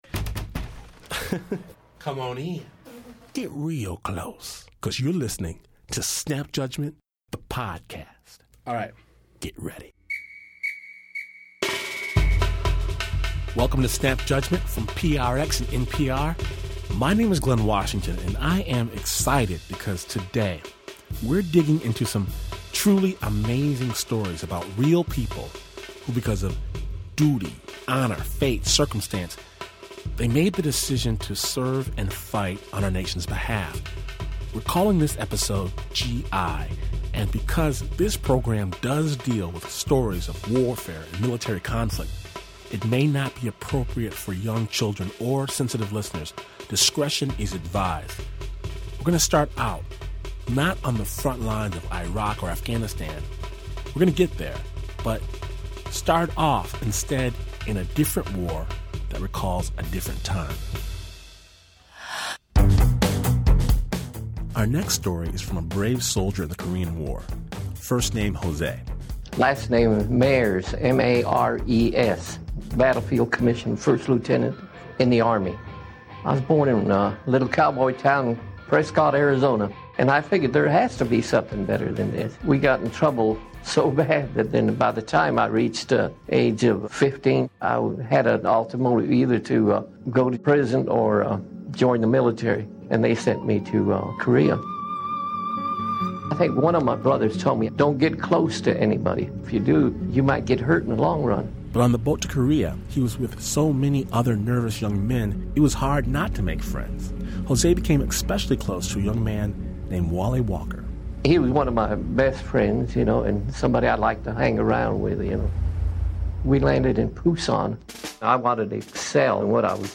Snap Judgment (Storytelling, with a BEAT) mixes real stories with killer beats